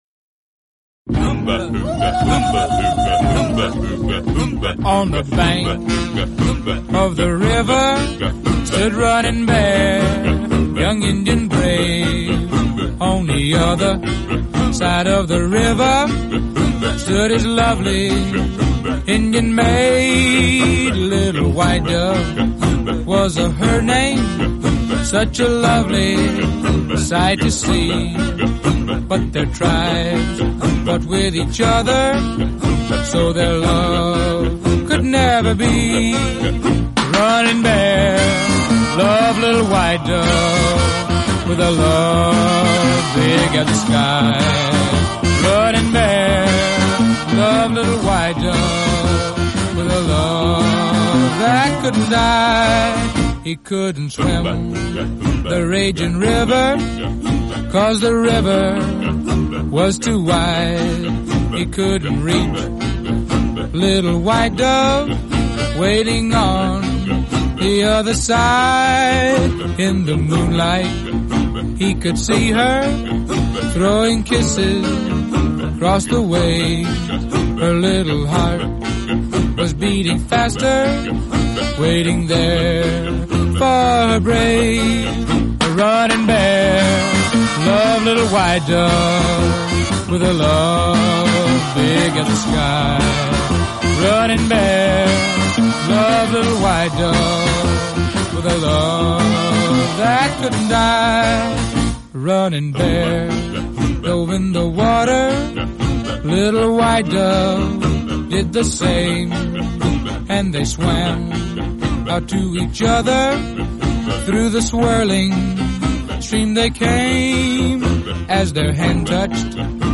создавая звуки индейцев.